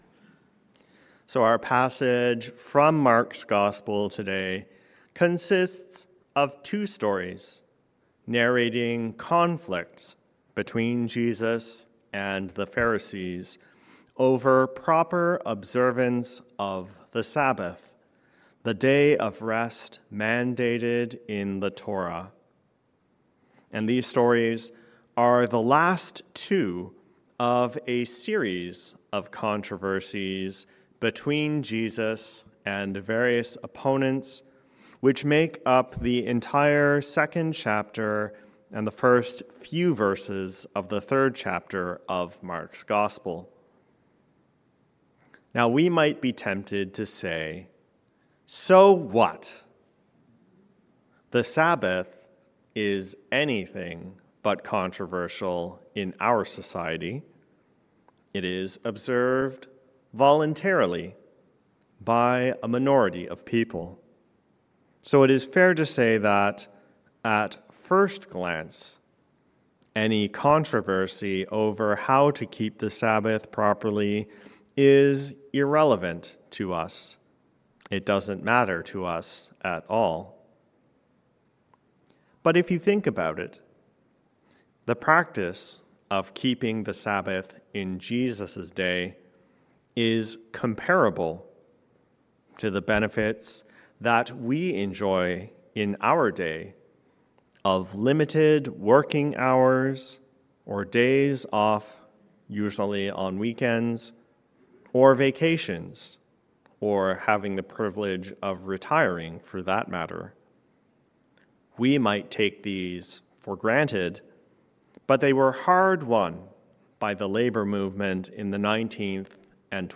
Sabbath Freedom. A Sermon on Mark 2:23-3:6.